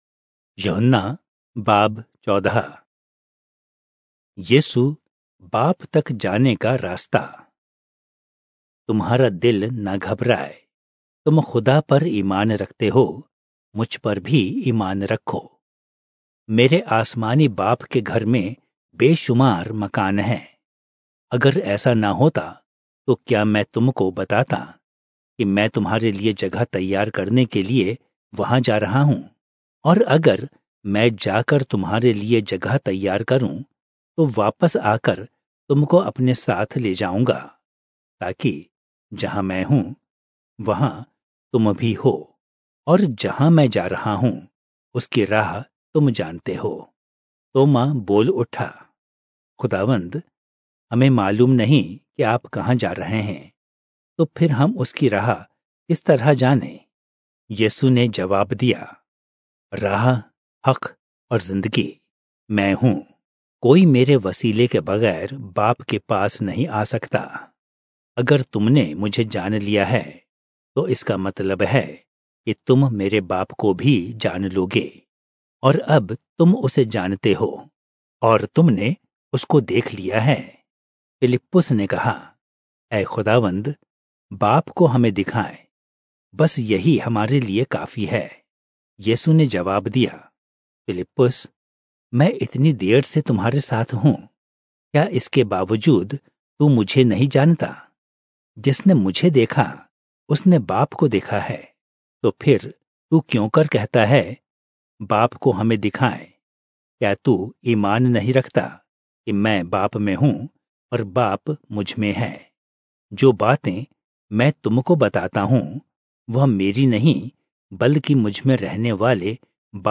Urdu Audio Bible - John 8 in Irvur bible version